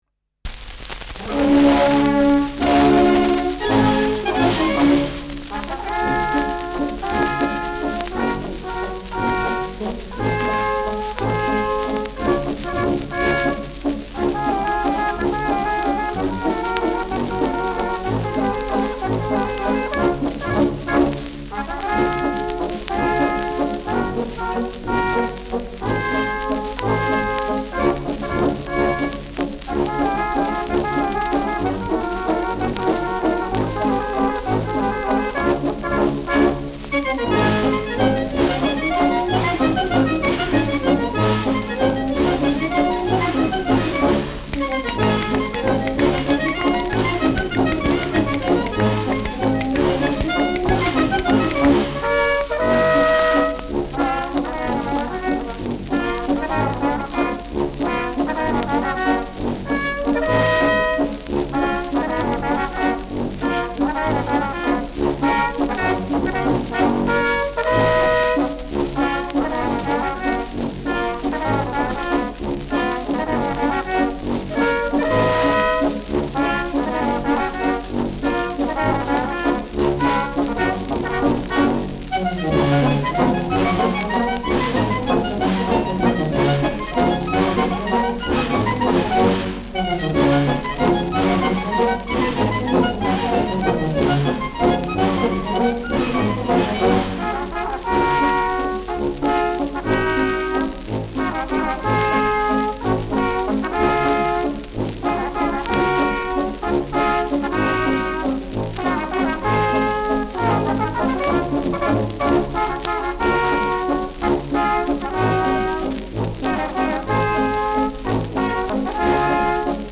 Schellackplatte
präsentes Rauschen : präsentes Knistern : vereinzelt leichtes Knacken
Mit Ausruf am Ende.
[Berlin] (Aufnahmeort)